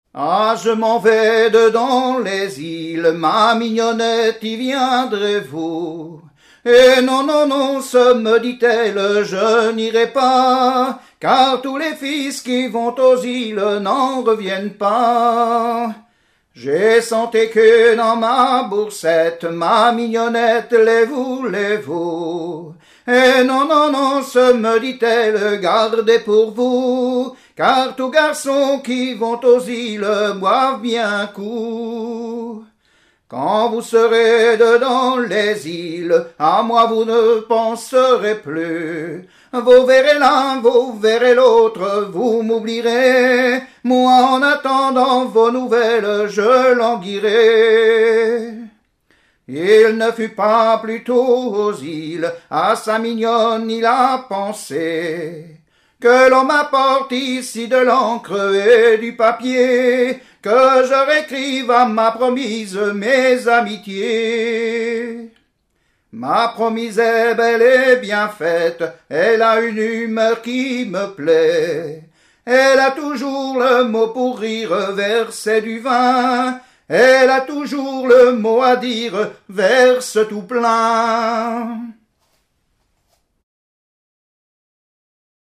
Bocage vendéen
Genre strophique
Catégorie Pièce musicale éditée